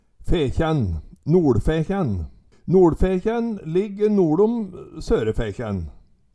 DIALEKTORD PÅ NORMERT NORSK Fekjæn
Høyr på uttala Ordklasse: Særnamn (stadnamn) Kategori: Stadnamn Attende til søk